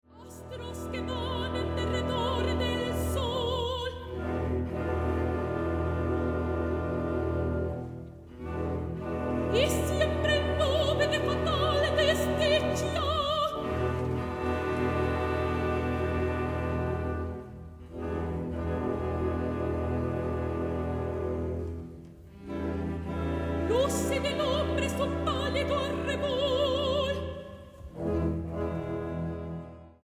ópera en 4 actos